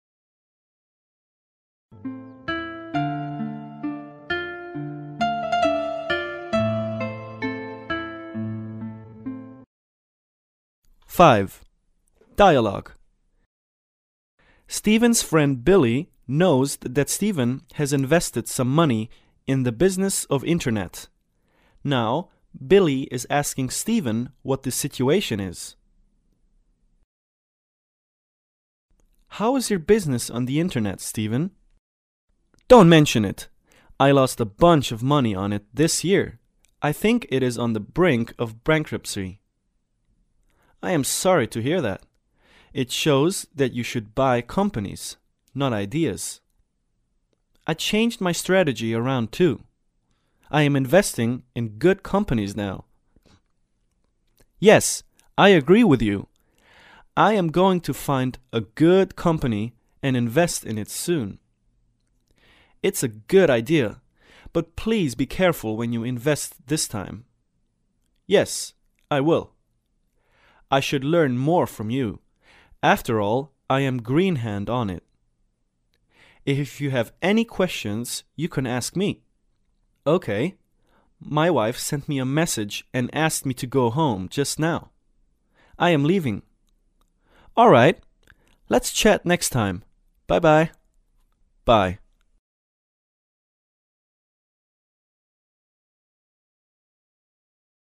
对话